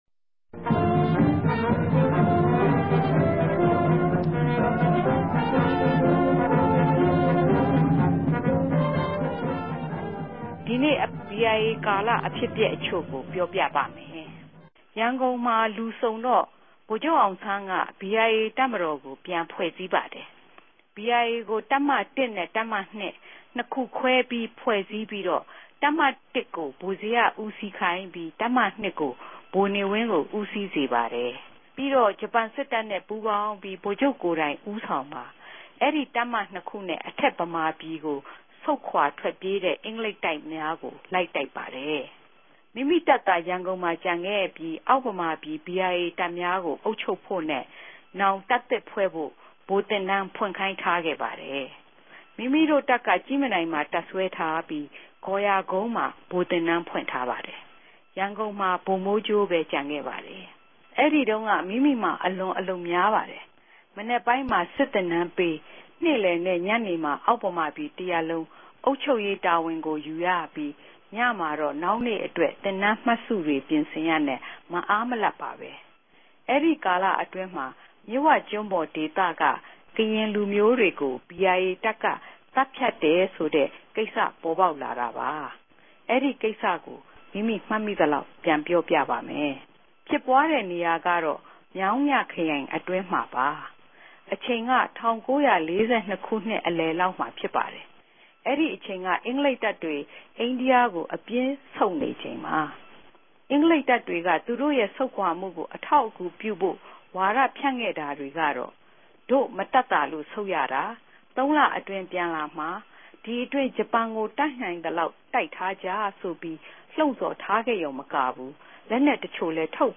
ဖတ်ကြား တင်ဆက်ထားပါတယ်။